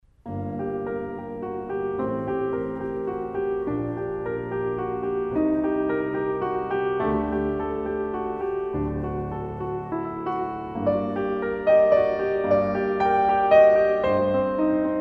Classical, Instrumental, Piano